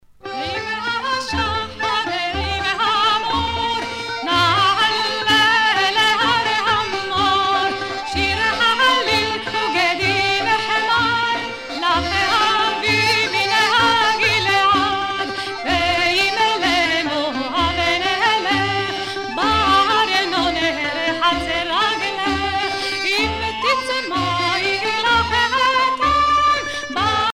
Chansons douces et chansons d'amour
Pièce musicale éditée